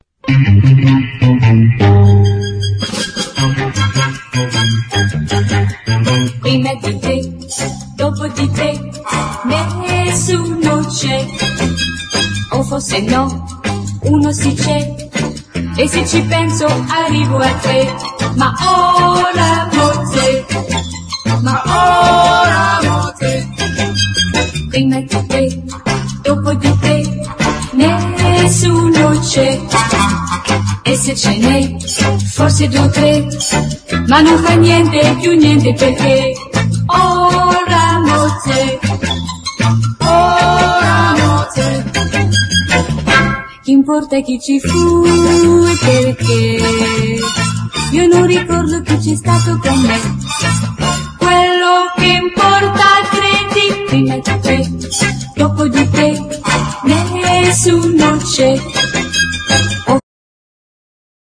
EASY LISTENING / VOCAL / FREE SOUL
フリー・ソウル・ライクでグルーヴィーな傑作！